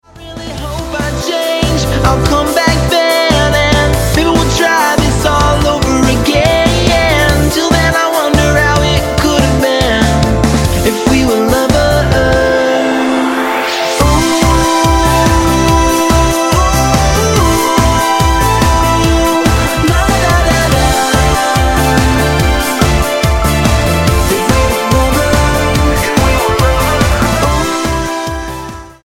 electronica pop band
Style: Dance/Electronic